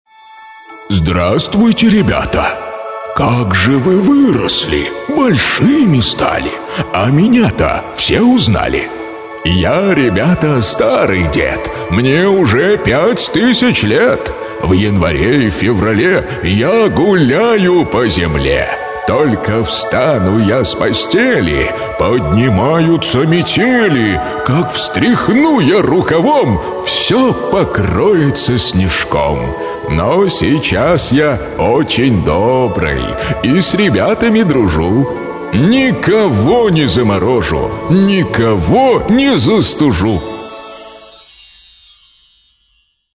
Голос Дедушки Мороза